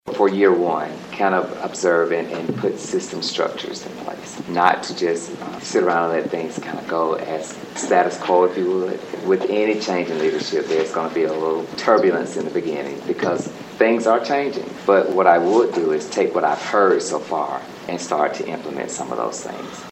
Monday night the Burr Oak School Board held second round interviews for the position.